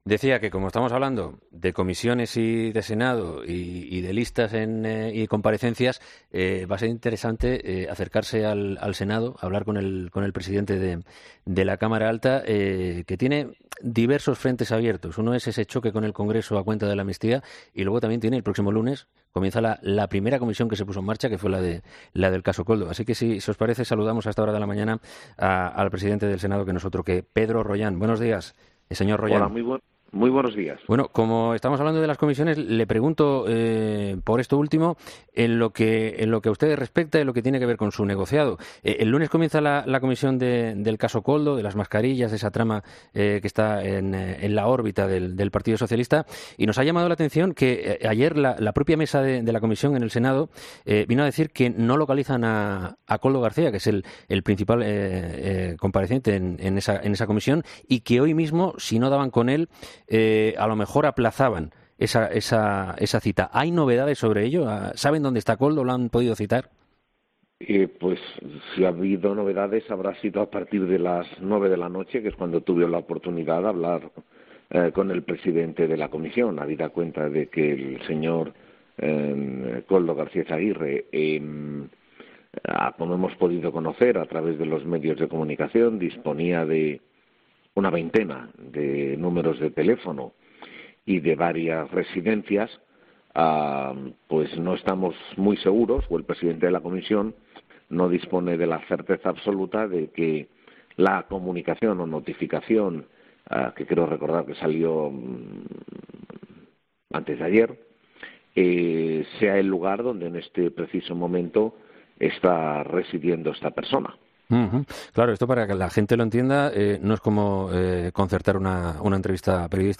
El presidente del Senado pasa este jueves por los micrófonos de 'Herrera en COPE' para reflexionar sobre la situación política actual y lo último sobre...